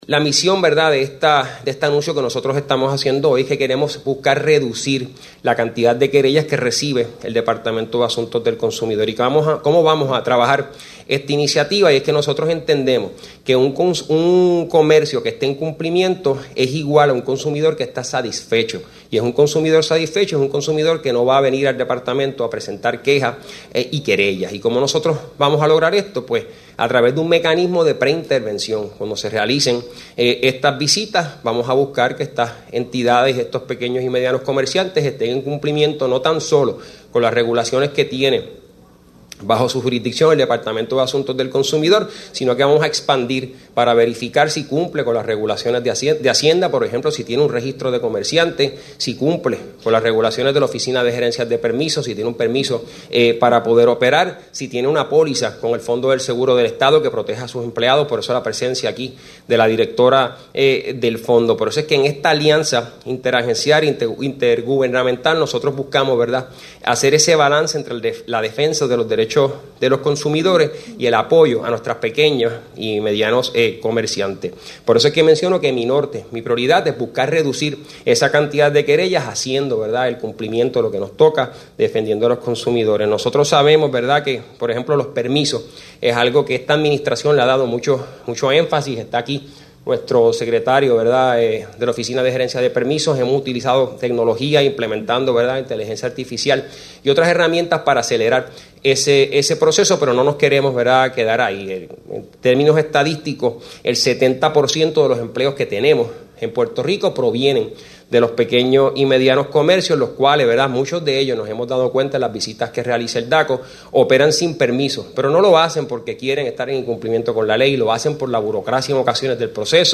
“Nuestra misión para el DACO se encuentra delineada en la férrea defensa de los derechos de los consumidores, garantizando que se cumplan todas las leyes y regulaciones al momento de estos adquirir un bien o servicio. Para esto, se hace imperativo que los comercios se adhieran a esas reglamentaciones en todo momento. Buscamos que todo comercio cumpla con el consumidor, y así no habrá necesidad de radicar querellas por violaciones, es un mecanismo de preintervención para asegurar, de primera instancia, que todo esté en ley y orden. Para apoyar esta política pública, hoy reactivamos la Oficina de Cumplimiento y Enlace Comercial”, dijo Torres Montalvo en conferencia de prensa celebrada en el Centro Gubernamental de Minillas.